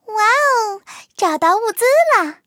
卡尔臼炮获得资源语音.OGG